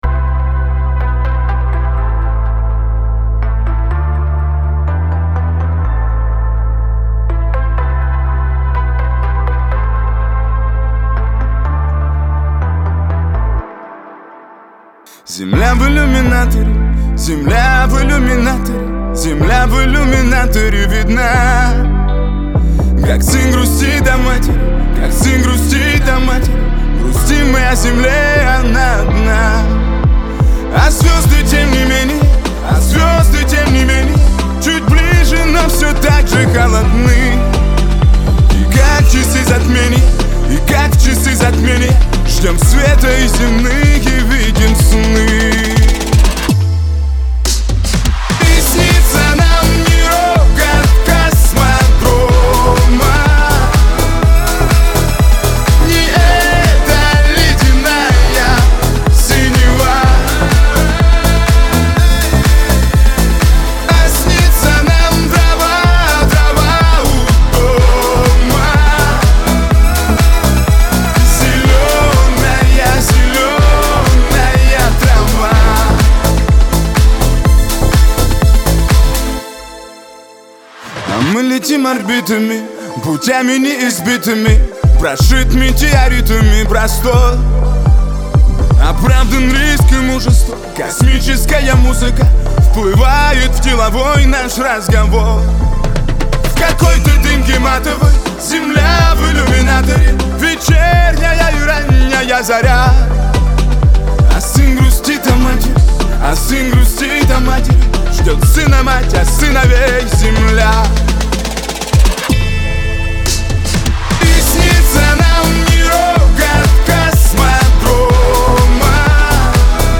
Кавер-версия
ХАУС-РЭП